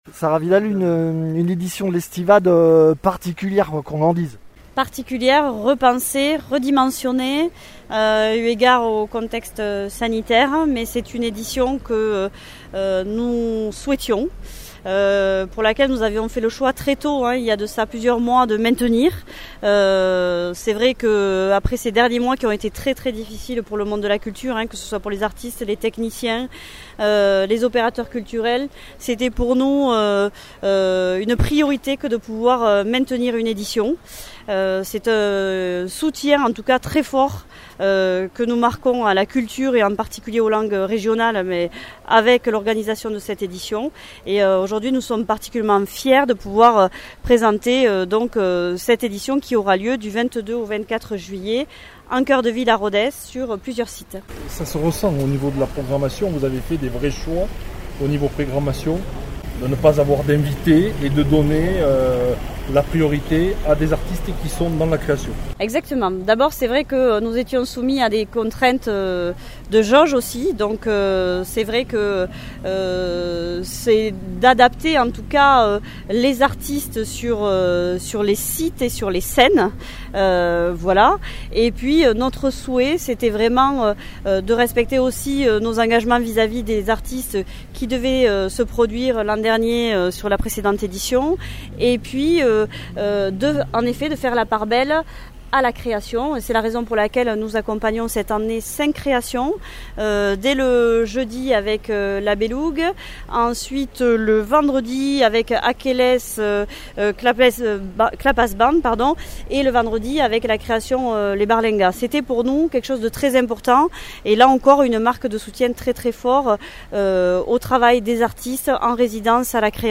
Interviews
Invité(s) : Sarah Vidal, adjointe à la culture à la mairie de Rodez